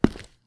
concrete_step_2_-02.wav